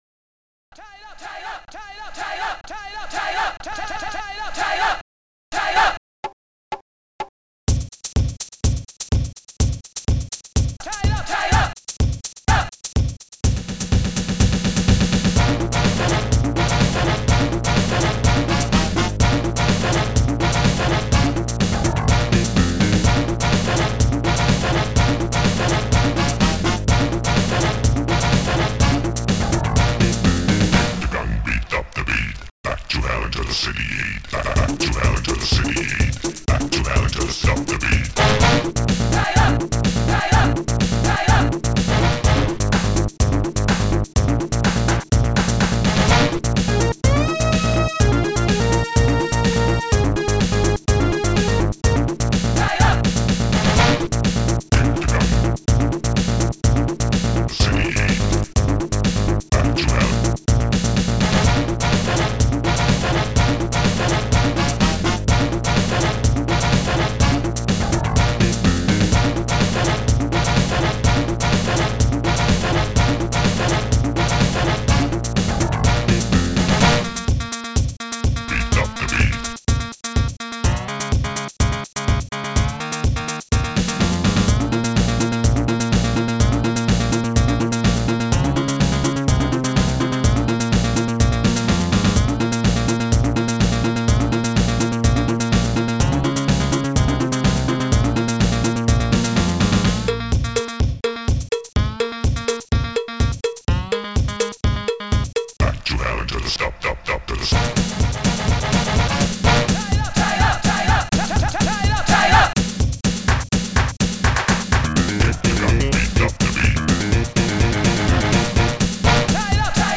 Instruments
st-01:bassdrum3
st-11:saxes
st-01:shaker
st-11:deepvoice1
st-11:moog1
st-11:akaisnare
st-11:bongo
st-11:flickbass
st-11:CZSTRING
st-01:steinway
st-11:cowbell1
st-01:claps1